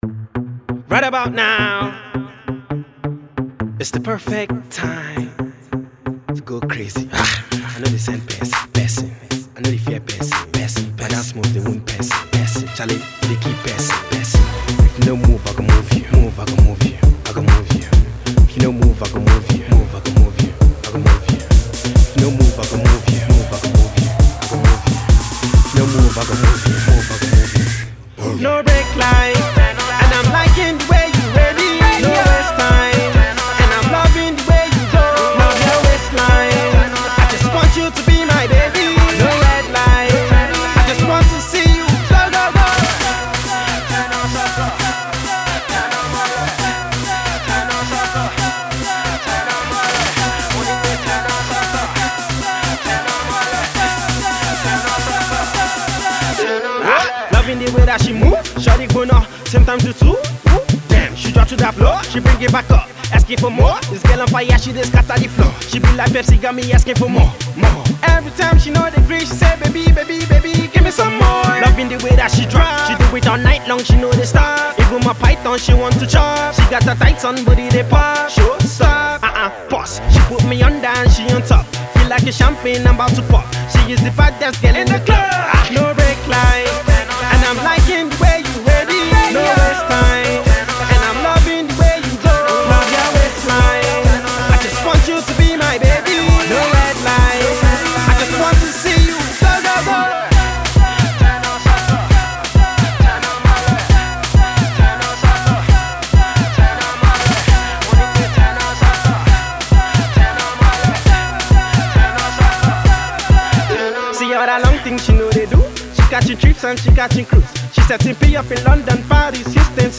This fast tempo energetic song will definitely move you.